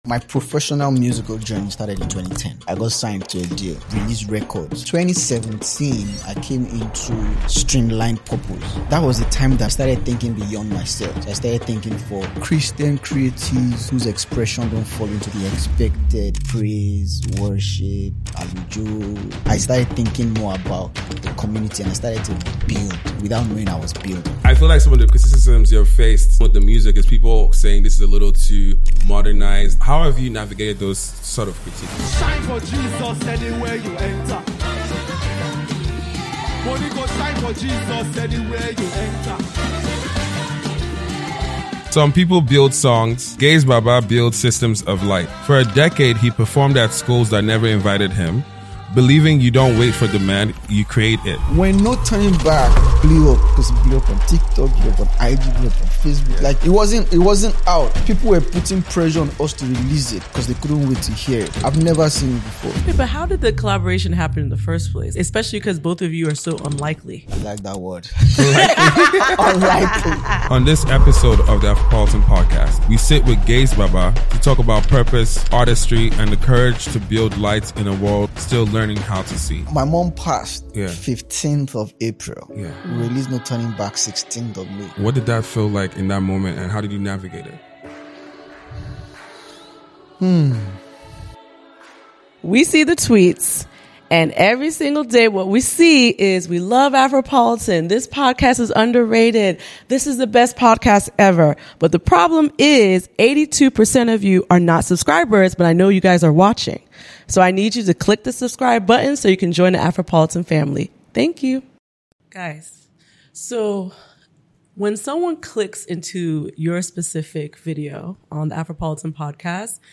This conversation explores how preparation meets purpose when nobody is watching.